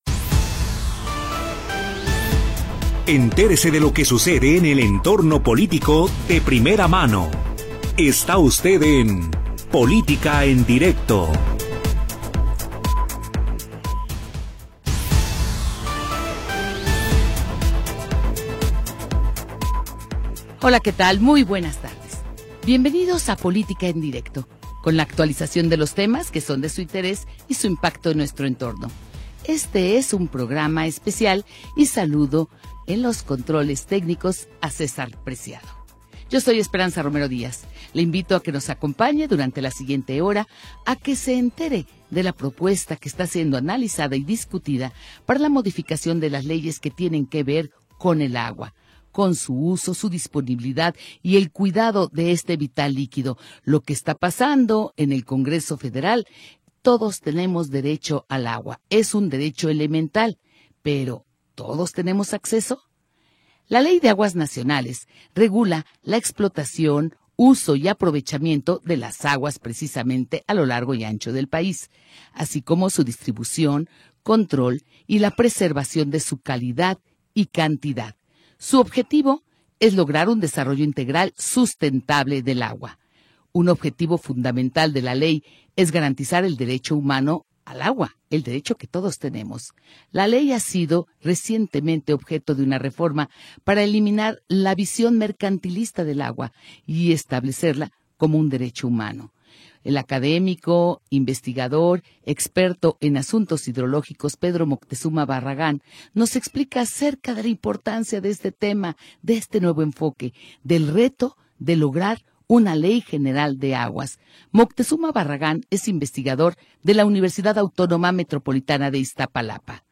Programa transmitido el 27 de Noviembre de 2025.